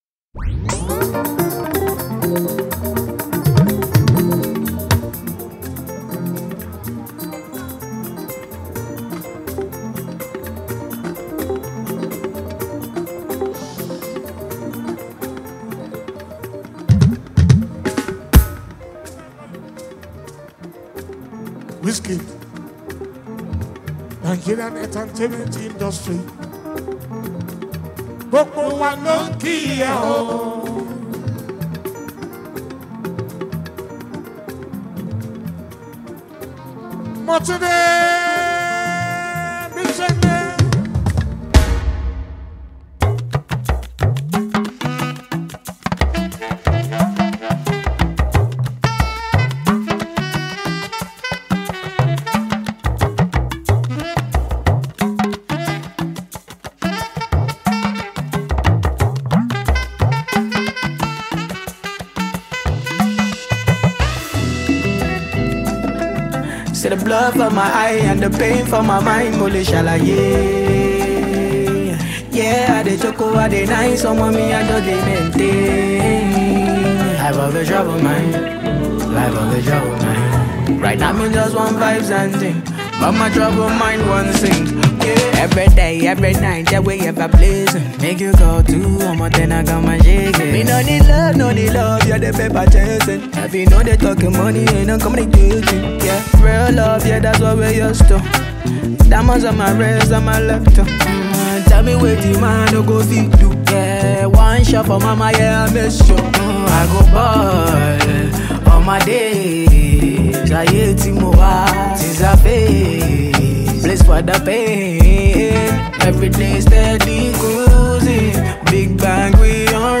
Afrobeats
With nice vocals and high instrumental equipments